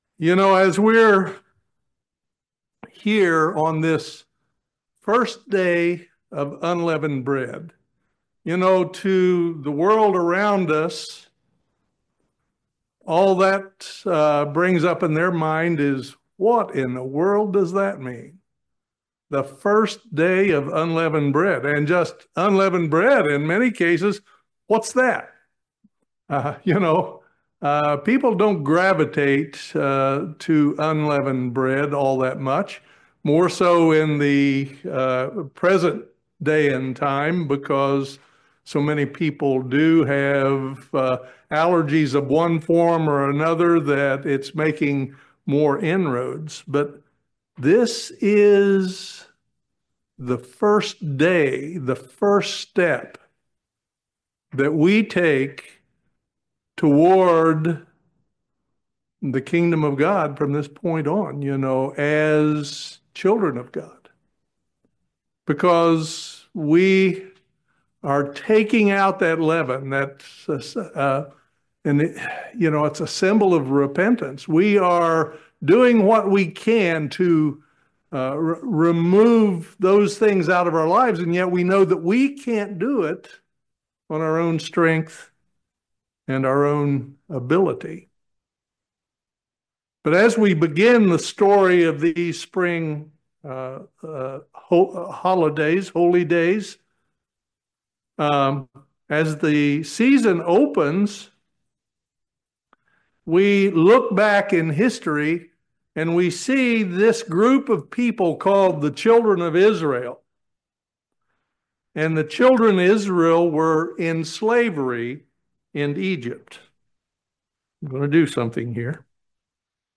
Join us for this very interesting video sermon on the story of the Spring Holy days.